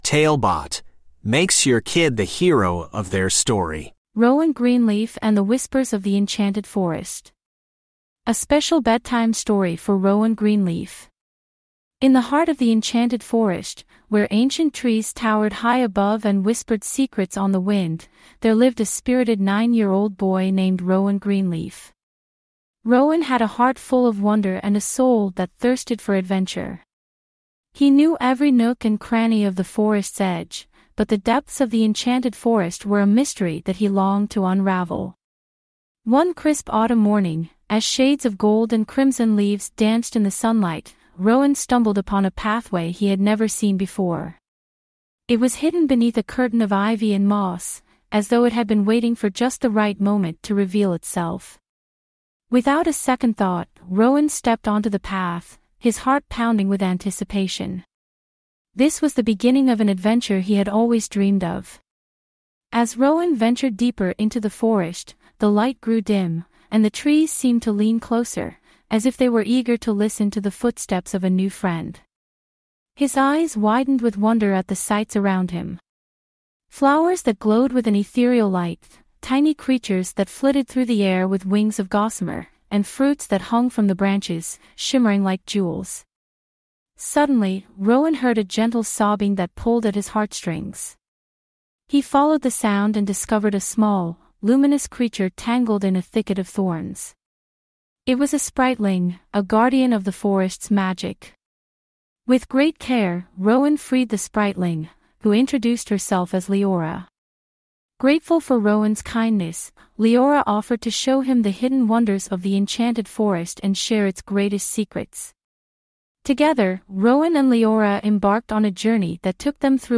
TaleBot Bedtime Stories
TaleBot AI Storyteller